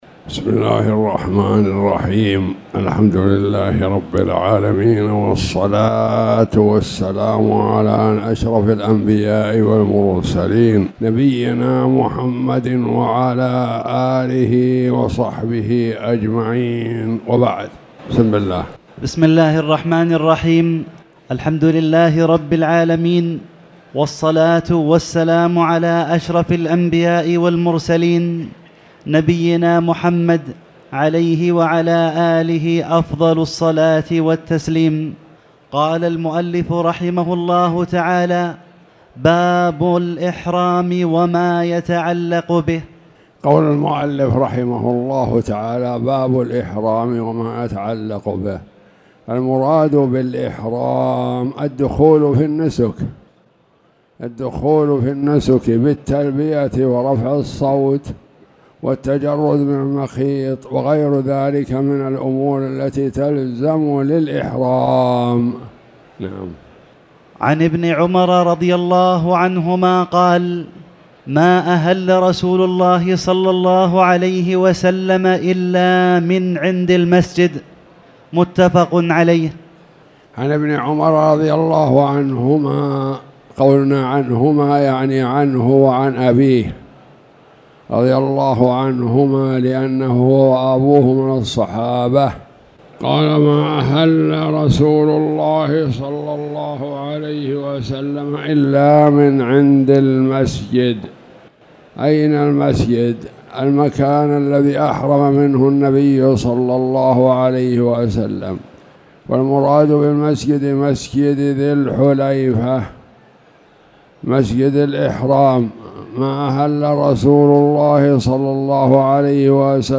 تاريخ النشر ١٥ جمادى الأولى ١٤٣٨ هـ المكان: المسجد الحرام الشيخ